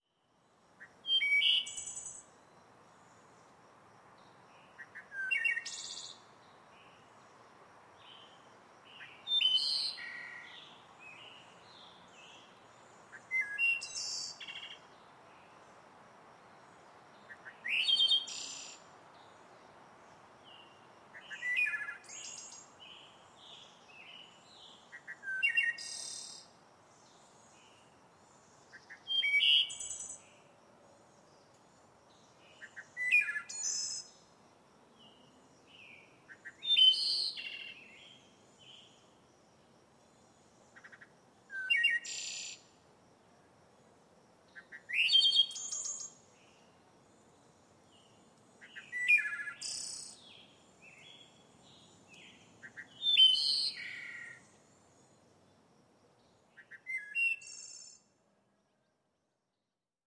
Здесь собраны натуральные записи солнечных дней: легкий ветер в кронах деревьев, стрекотание кузнечиков, плеск воды и другие уютные звучания.
Шум приятной погоды во дворе